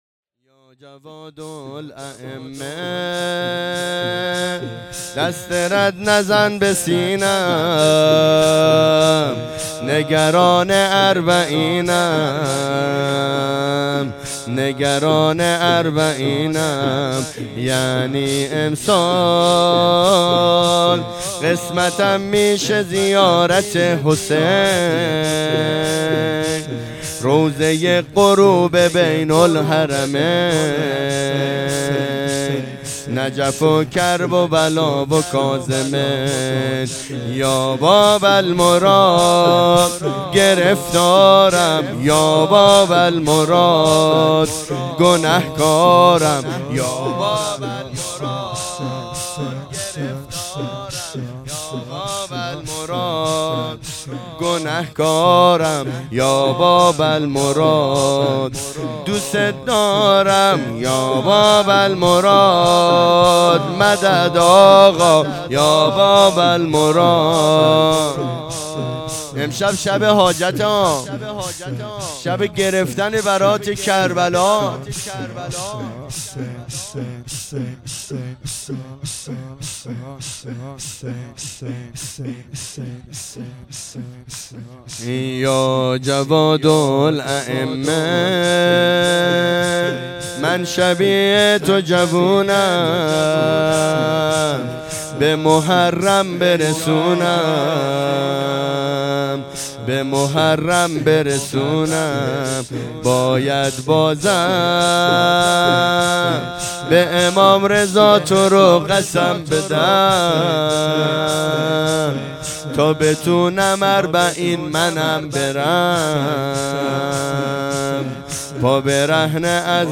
شهادت امام جواد علیه السلام 1404